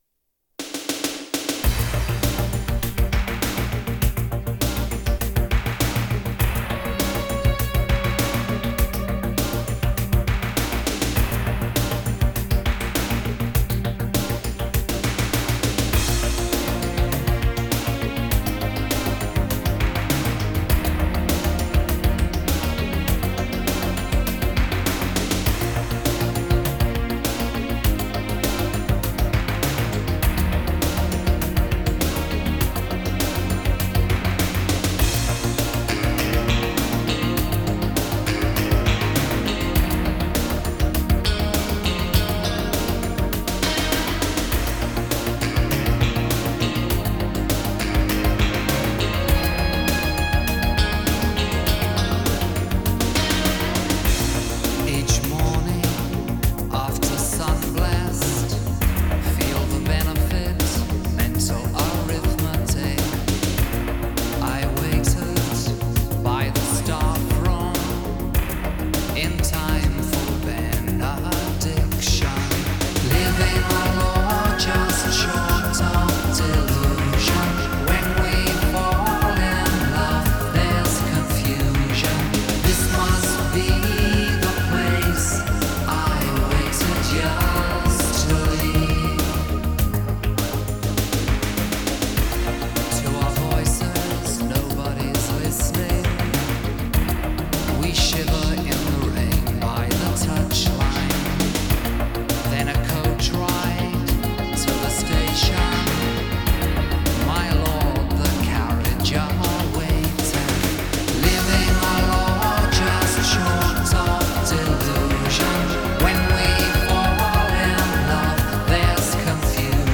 流行、电子、合成流行